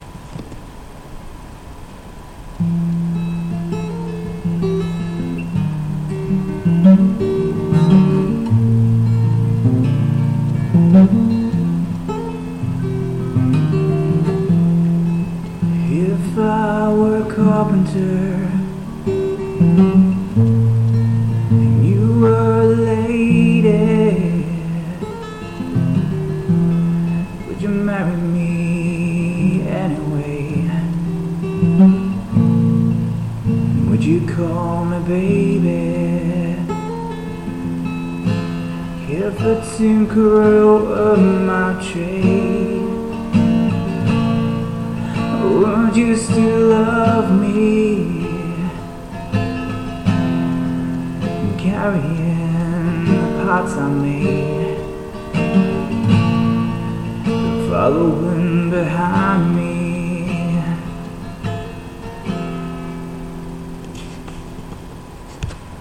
I enjoy playing, though I'm a terrible singer.